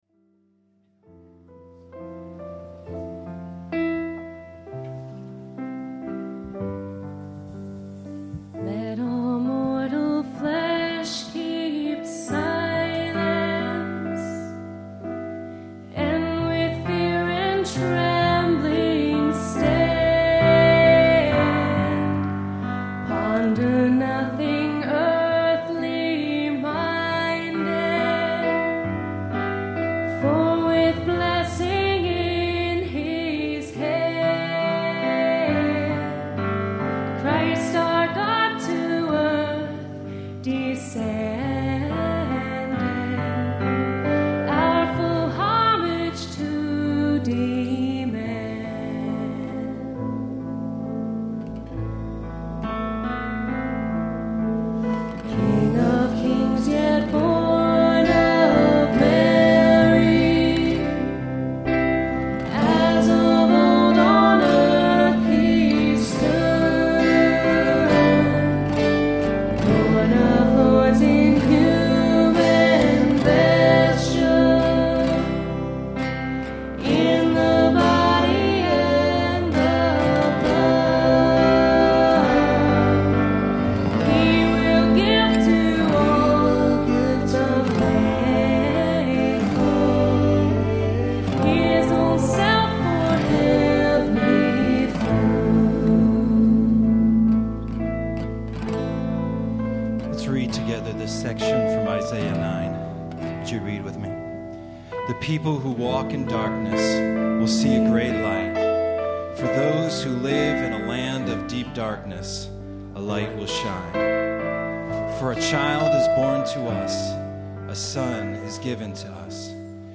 As played at Terra Nova's Christmas Eve service 2009.